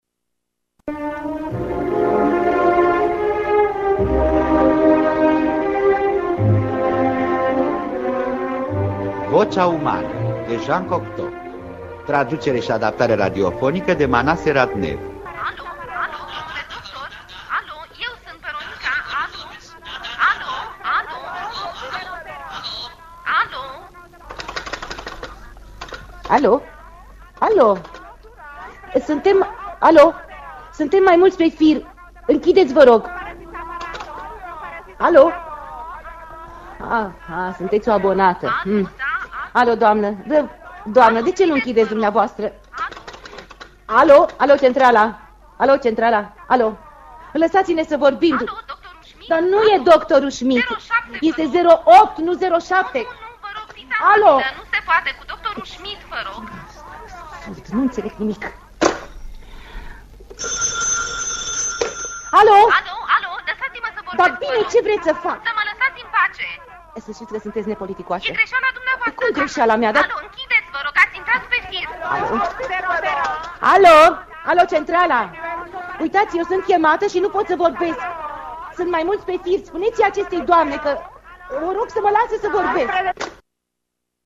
Pe scena teatrului naţional radiofonic, o femeie singură- Gina Patrichi şi un telefon. La capătul firului, undeva… un Bărbat.
Pe scena teatrului radiofonic,o femeie singură-Gina Patrichi şi un telefon: